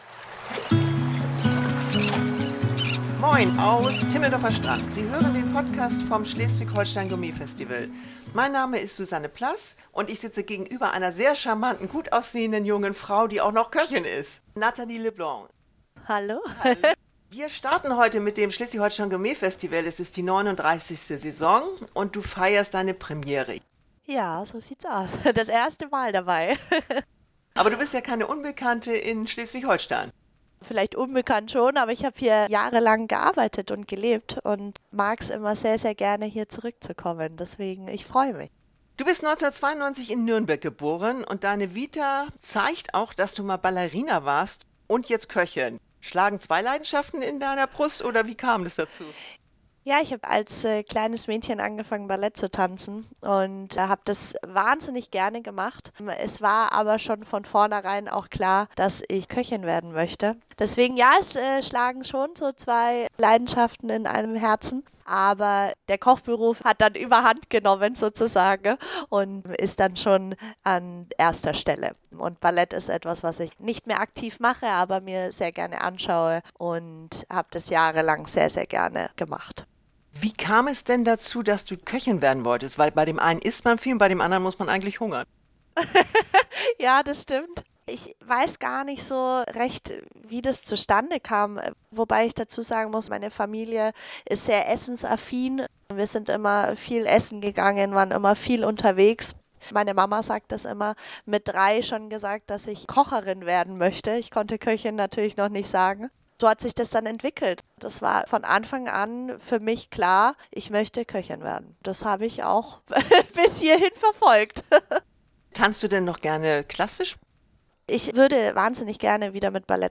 Ein mitreißendes Hörerlebnis von einer reflektierten und zielstrebigen jungen Frau, deren Karriere erst begonnen hat.